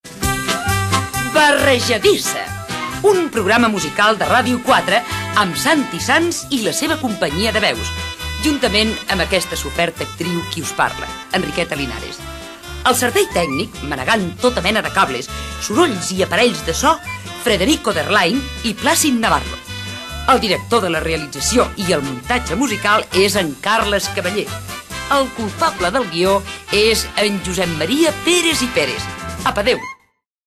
Presentació del programa, amb els integrants de l'equip
FM